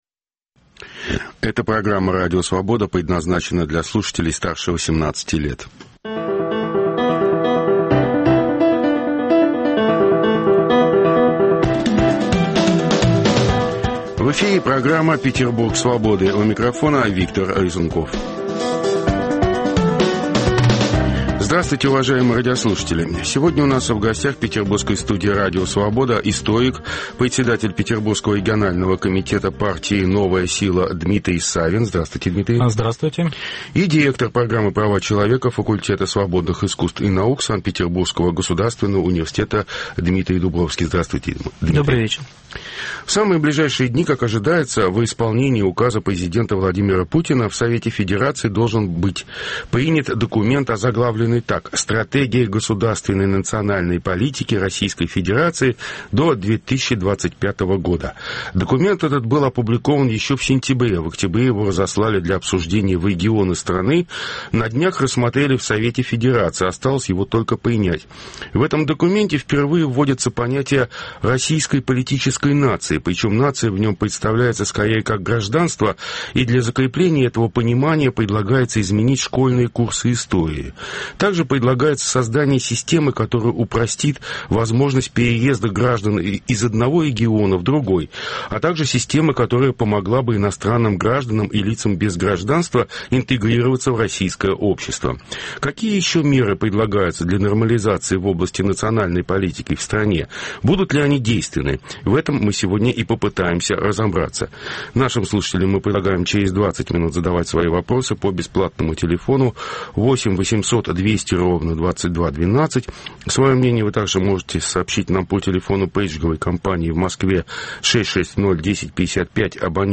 Круглый стол: Петербург Свободы